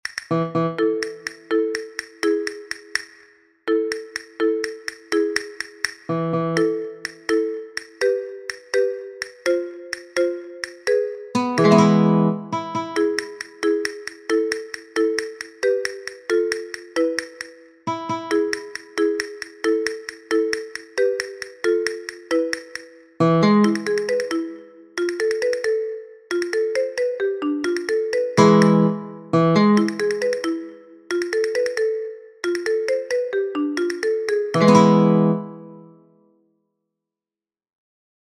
Guitar and castanets are the most frequent instruments that accompany these melodies.
El Vito accompaniment
Finally, here you have got the sound file corresponding to the accompaniment.